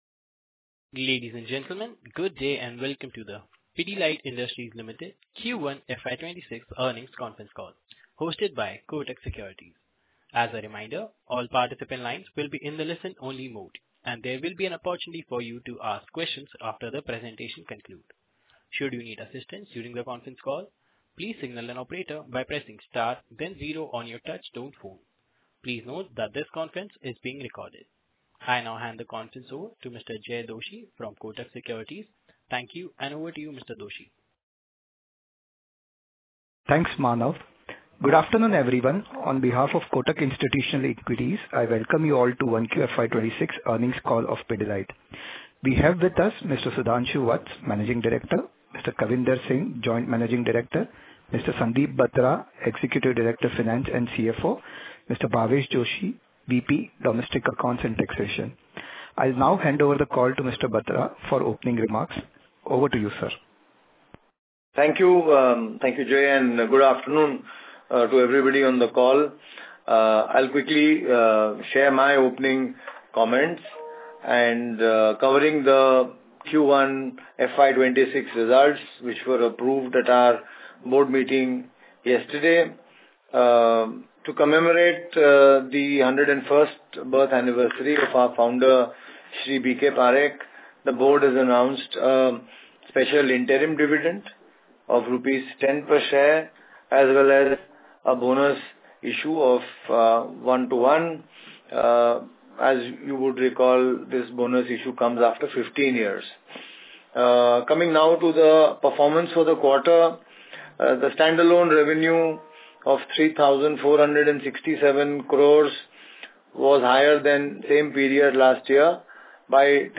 Pidilite Industries Limited Q4FY24 Earnings call audio clip